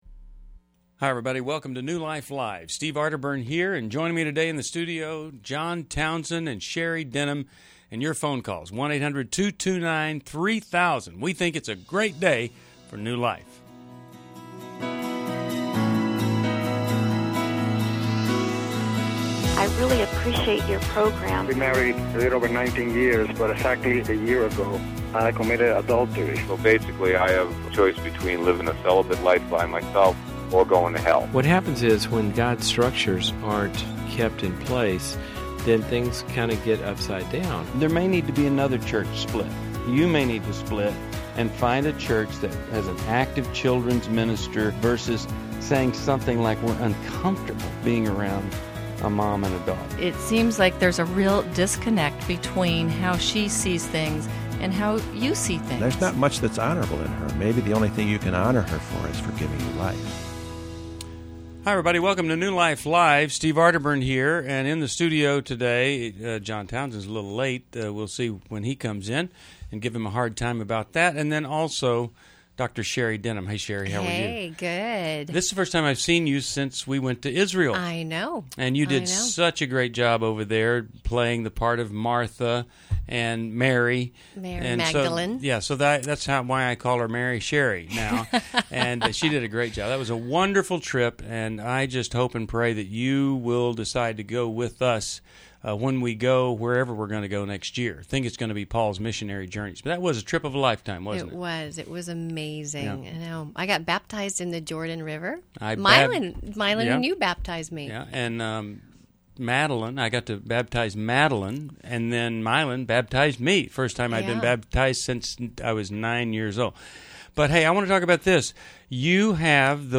Explore dating, parenting, marriage, and addiction insights in New Life Live: July 5, 2011. Hosts tackle tough caller questions for healing and growth.